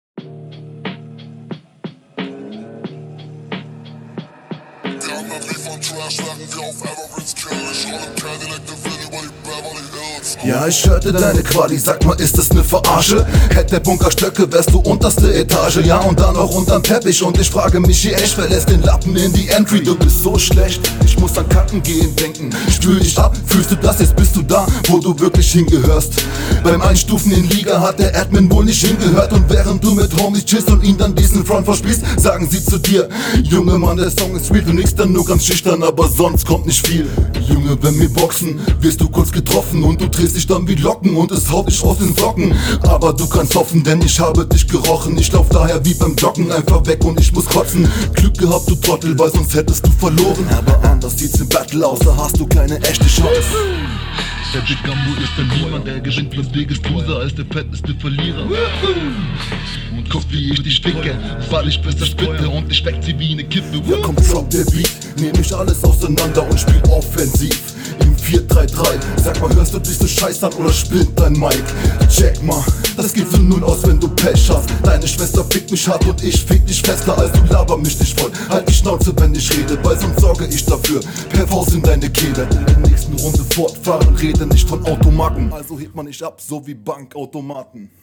Stimme bisschen zu laut auf dem Beat, aber der Stimmeinsatz ist hier sehr cool.
Flow: Du flowst absolut gechillt und kommt richtig gut auf dem Beat.